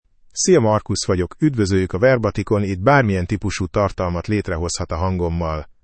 MaleHungarian (Hungary)
MarcusMale Hungarian AI voice
Voice sample
Listen to Marcus's male Hungarian voice.
Marcus delivers clear pronunciation with authentic Hungary Hungarian intonation, making your content sound professionally produced.